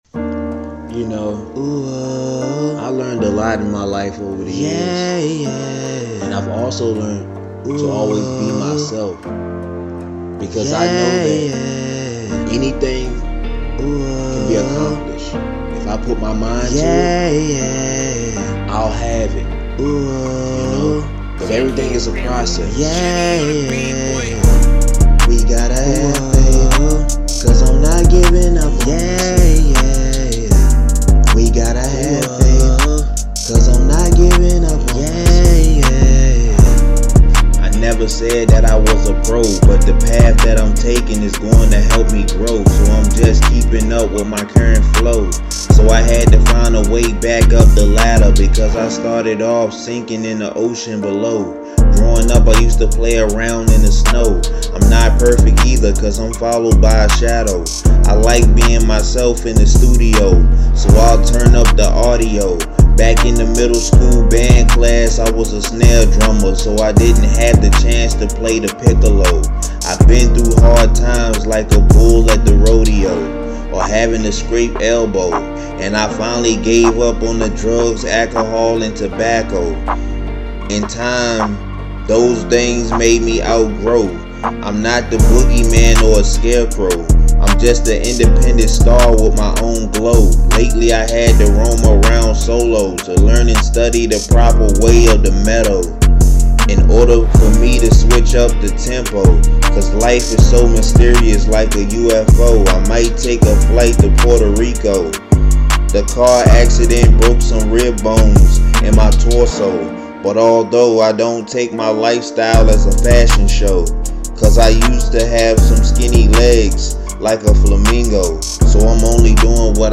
Hiphop
uplifting and inspirational song